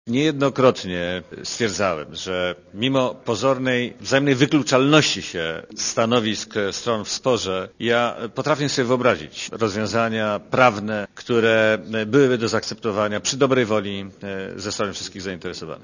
Dla Radia Zet mówi minister Cimoszewicz (62 KB)